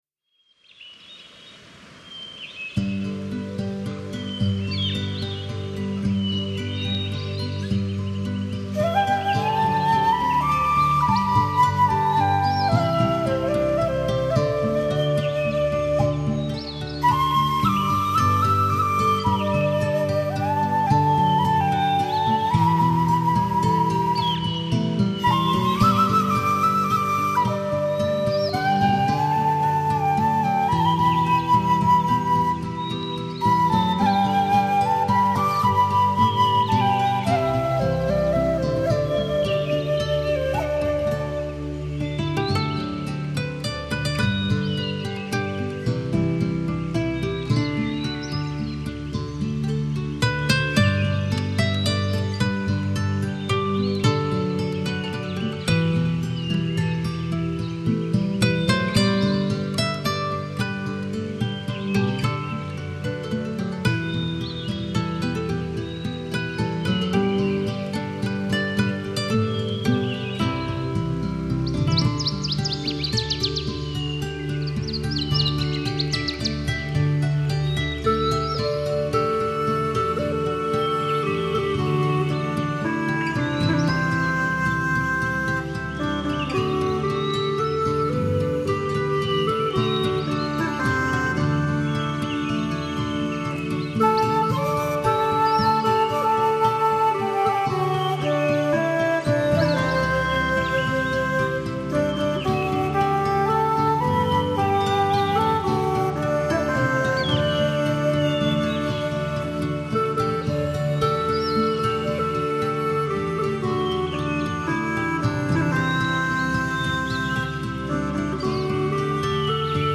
音樂，讓妳置身于壹個充滿綠色的如夢仙境，陶醉于大自然韻律的和諧氛圍，得到完美釋放，壓力也隨之雲散飄離。